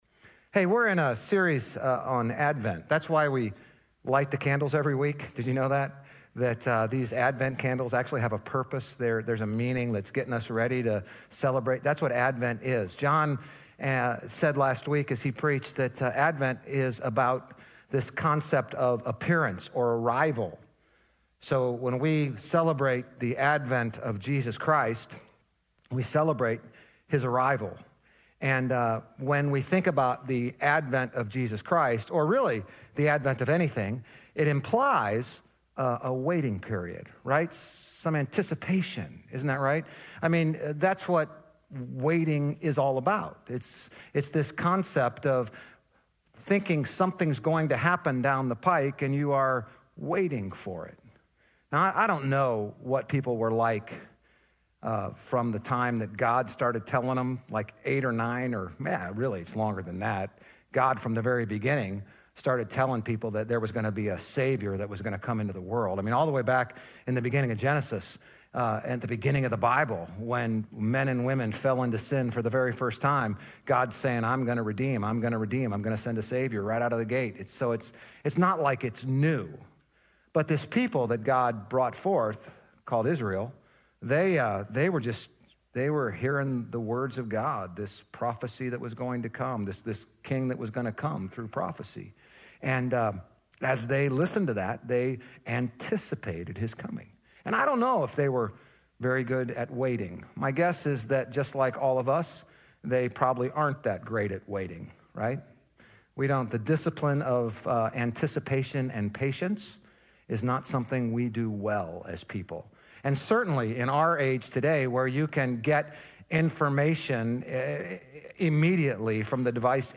GCC-OJ-December-4-Sermon.mp3